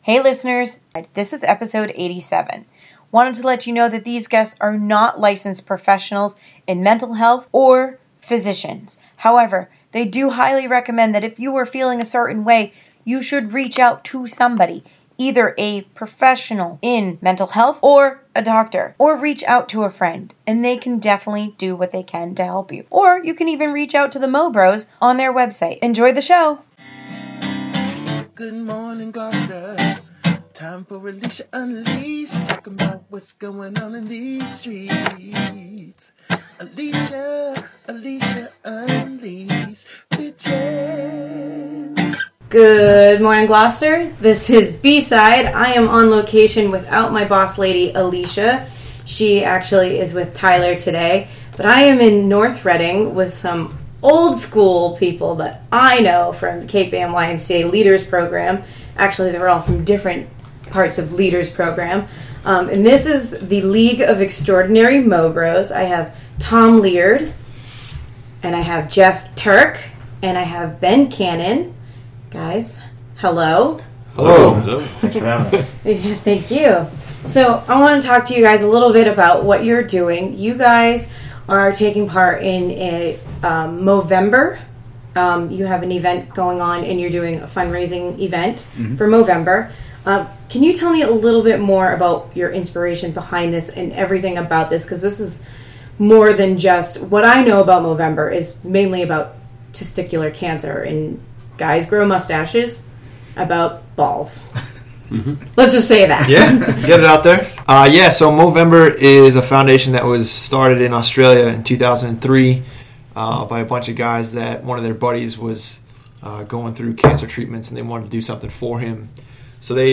on location